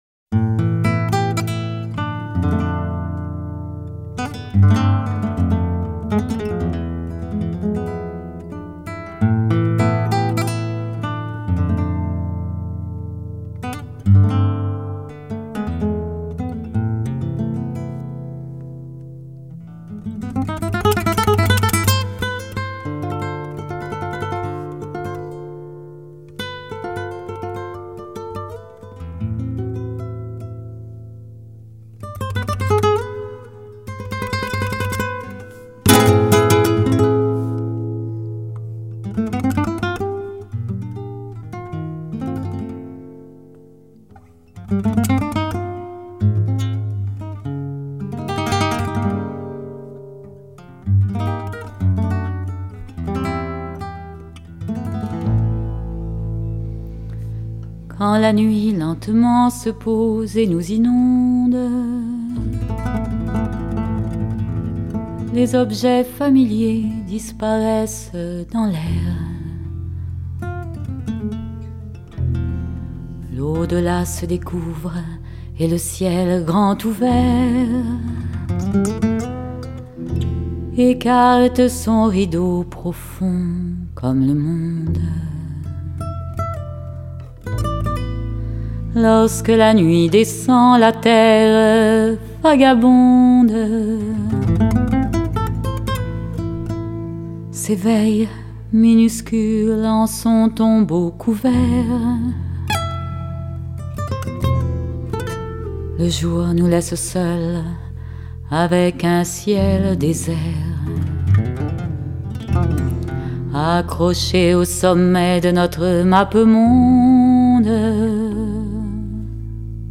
各族音樂家聯手撥動．詩與歌靈魂的對話
一種”世界音樂”的觀點。